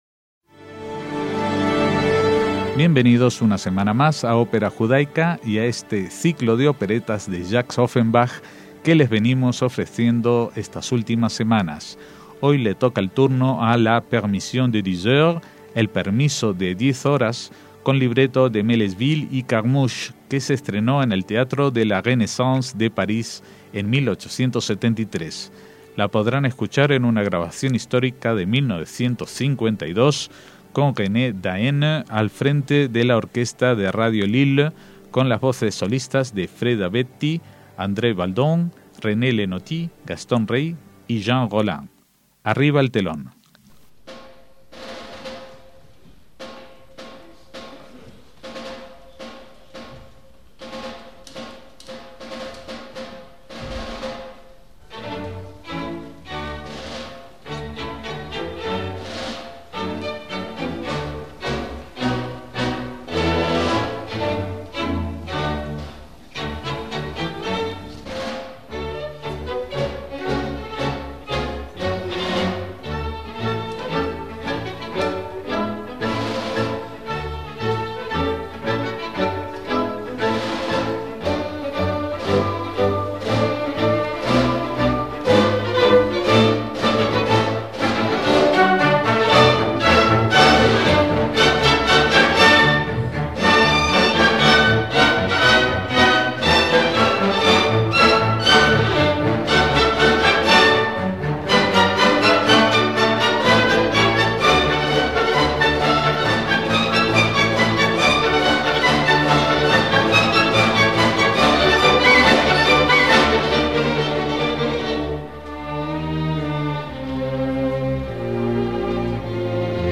ópera cómica en un acto
La oiremos en una grabación de 1952